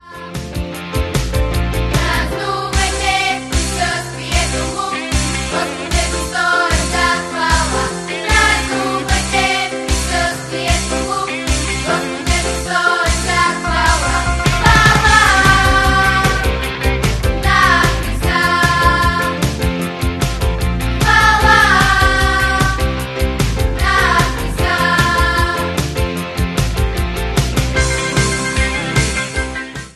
Инструментали на всички песни...